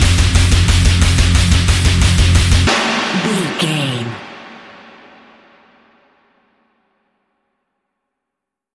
Aeolian/Minor
drums
electric guitar
bass guitar
hard rock
lead guitar
aggressive
energetic
intense
nu metal
alternative metal